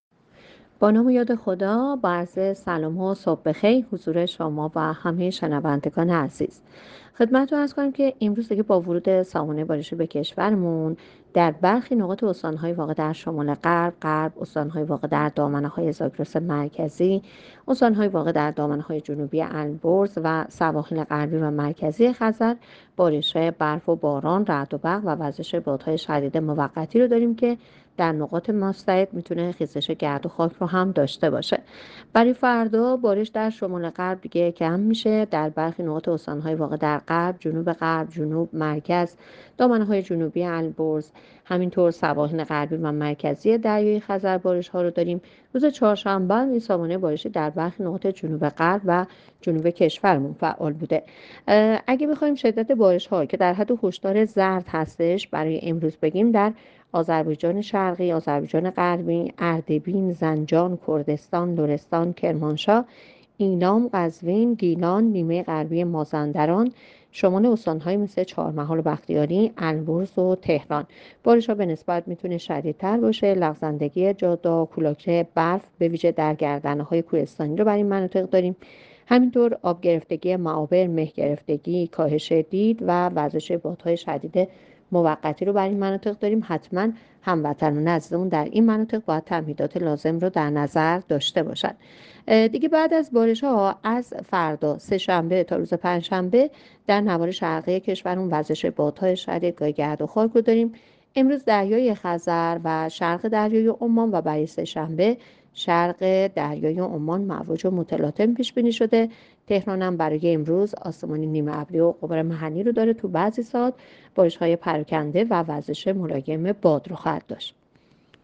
گزارش رادیو اینترنتی پایگاه‌ خبری از آخرین وضعیت آب‌وهوای ۱۷ دی؛